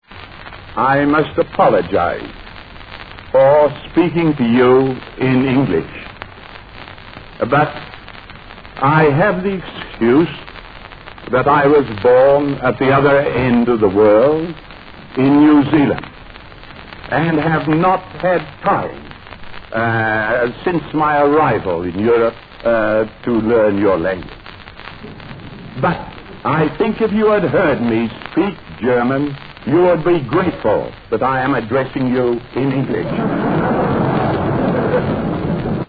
The meeting was chaired by Max Born who introduces Rutherford.
Hear Rutherford's Voice   (Speaking in English.  0 min 35 sec, 70KB, MP3)